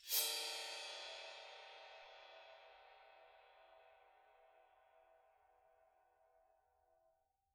susCymb1-scrape1_v1.wav